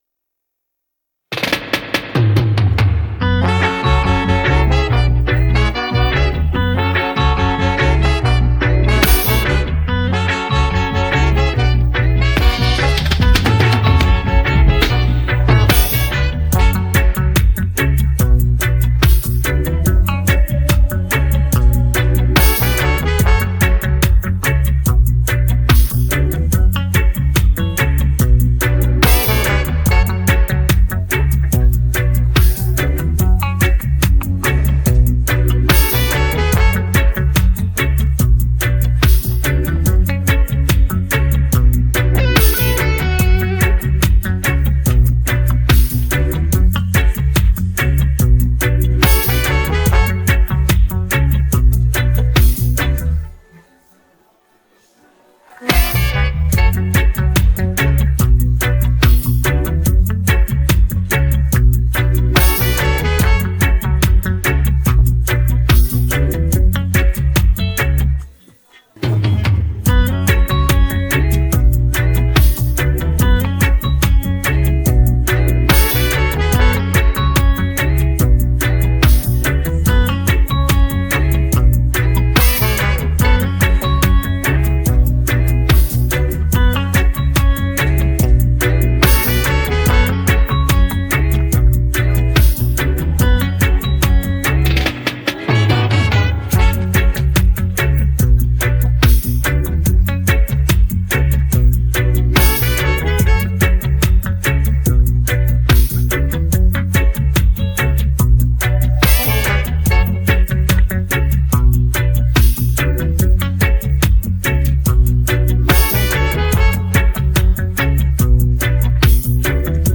Afro dancehallAfrobeatsDancehall